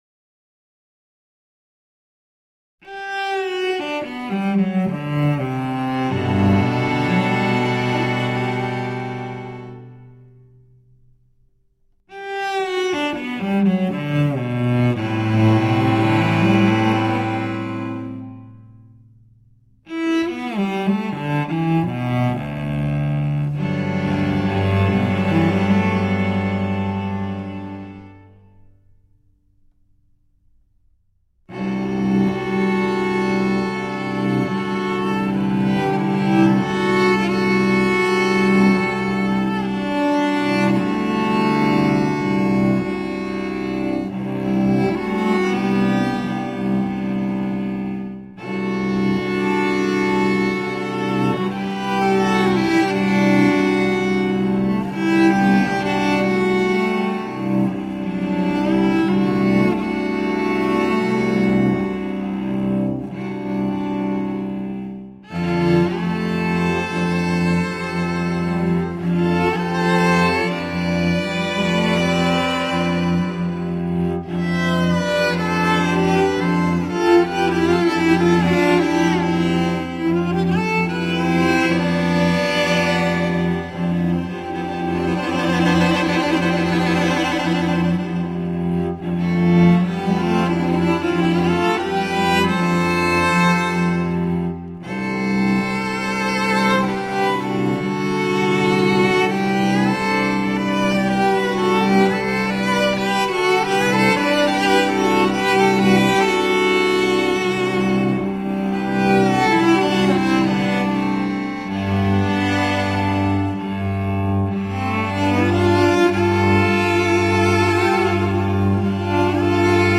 Scored for cello quartet